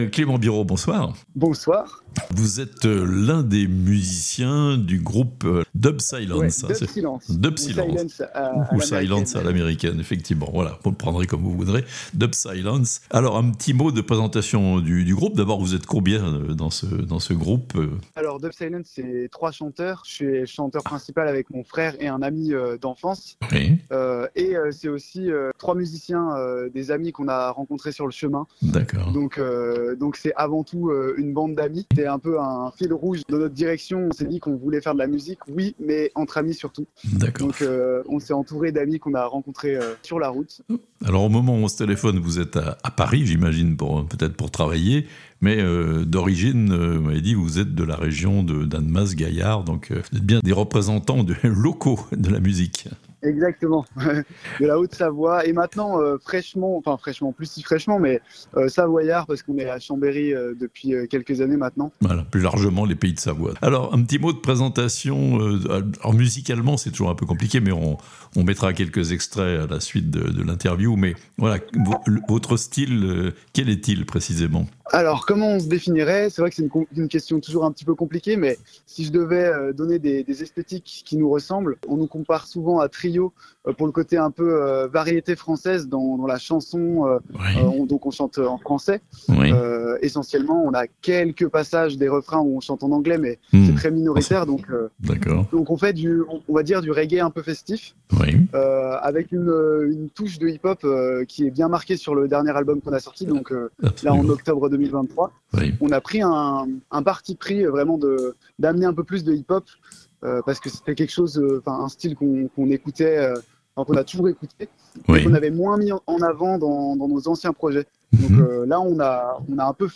Les savoyards du groupe Dub Silence, sur la scène de Festigrat's, le festival de musique de Praz-de-Lys/Sommand (interview)